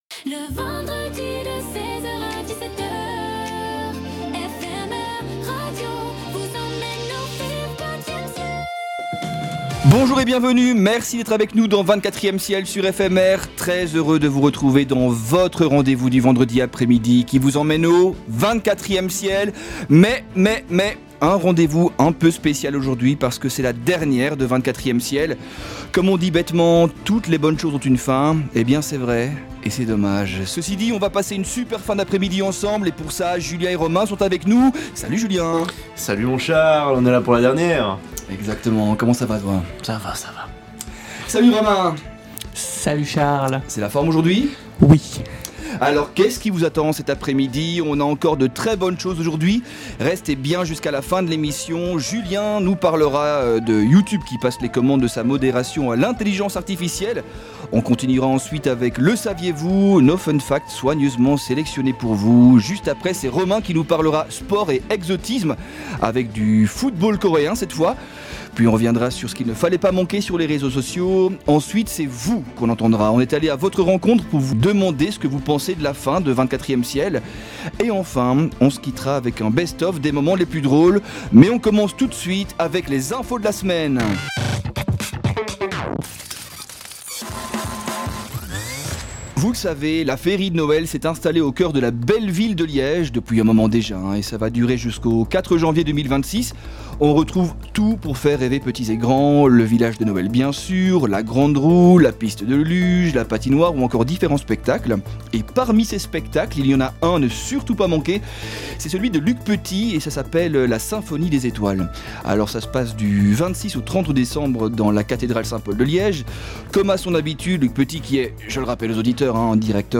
Sans oublier la parole aux auditeurs ainsi qu'un best-of en fin d'émission !